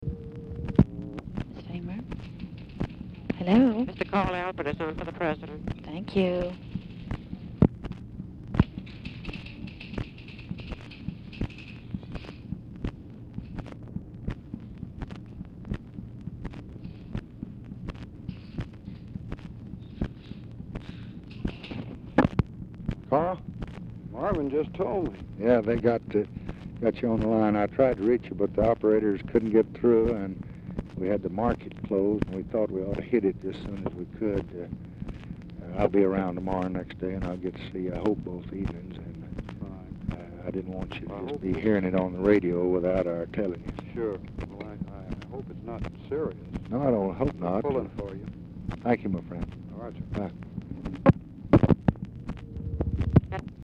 Telephone conversation # 9014, sound recording, LBJ and CARL ALBERT, 10/5/1965, 5:54PM | Discover LBJ
Format Dictation belt
Location Of Speaker 1 Oval Office or unknown location